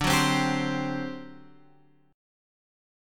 D11 chord {10 9 10 x 8 8} chord